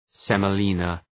Shkrimi fonetik {,semə’lınə}